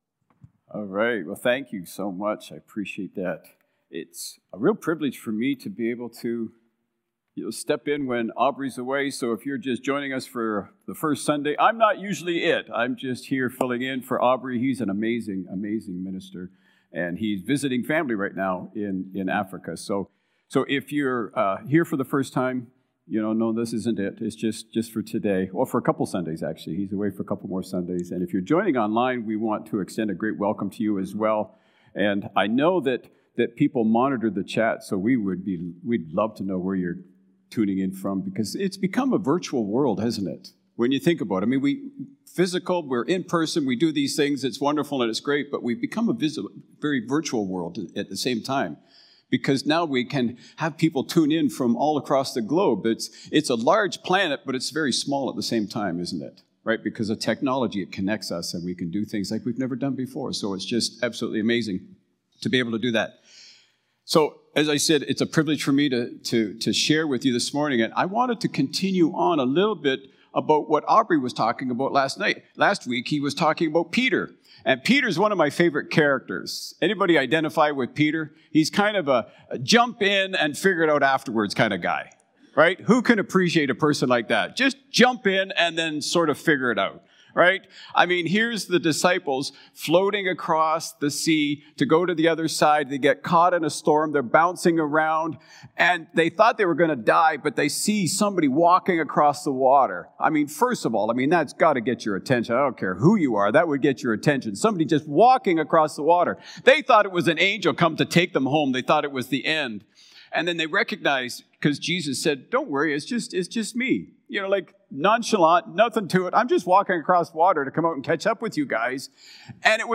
February-2-Sermon.mp3